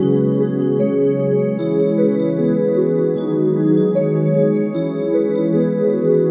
标签： 152 bpm Trap Loops Bells Loops 1.06 MB wav Key : Bm FL Studio
声道立体声